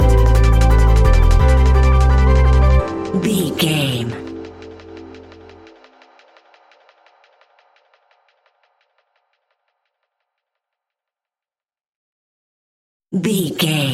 Ionian/Major
A♯
electronic
techno
trance
synths
synthwave
instrumentals